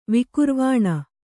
♪ vikurvāṇa